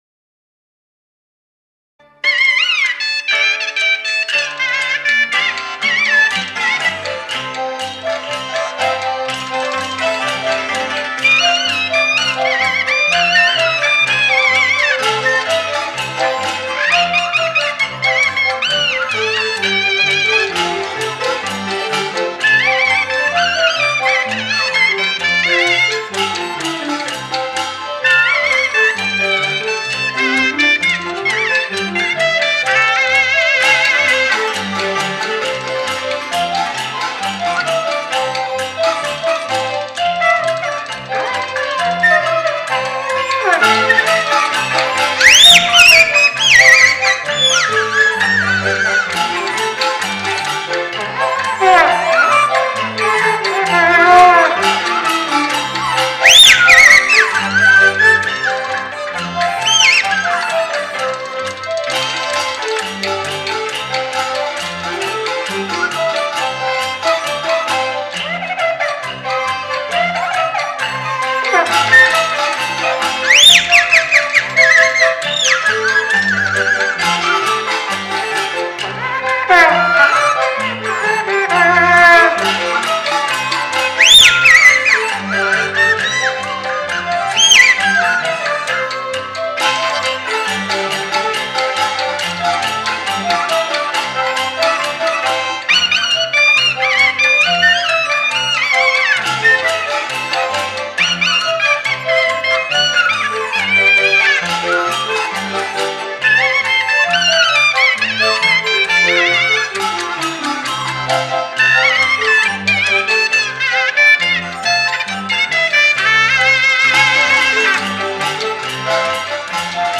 并采用多种唢呐的吹奏形式模仿不同人物，乡土音乐 具有浓厚的地方特色。